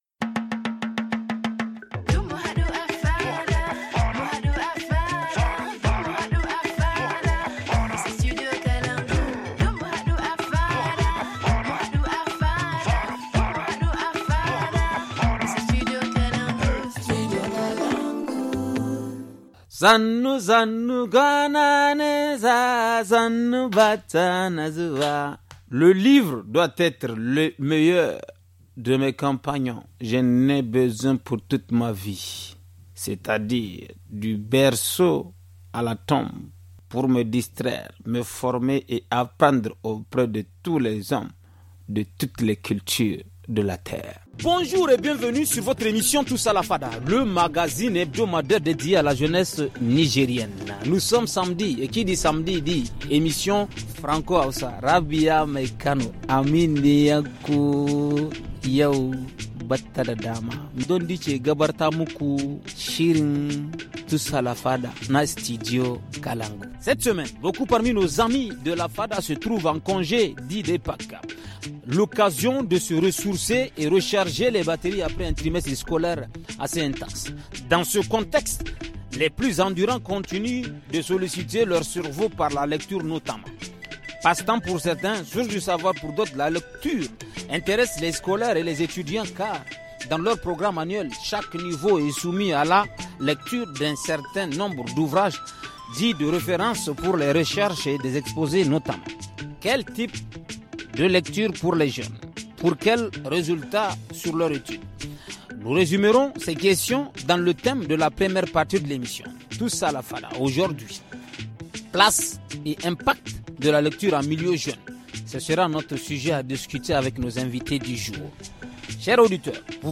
professeur de philosophie
jeune lecteur
passionné de la lecture